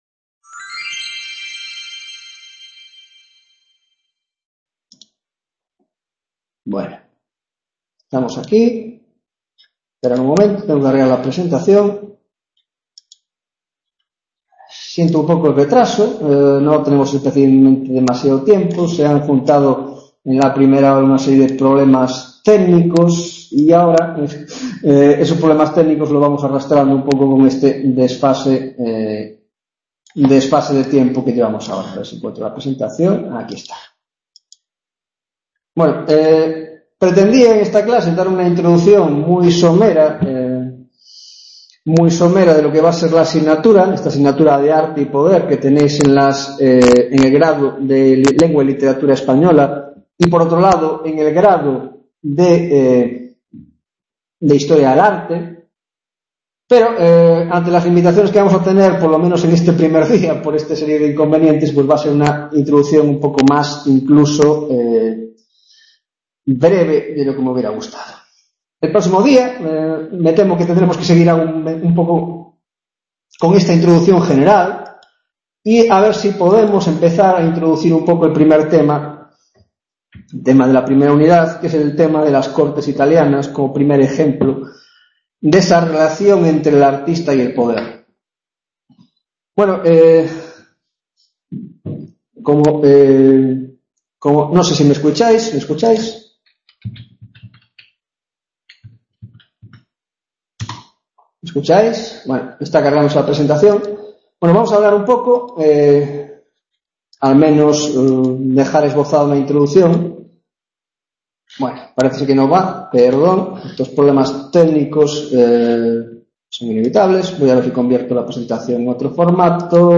1ª Tutoria de la asignatura Arte y Poder en la Edad Moderna - La Figura del Artista y el poder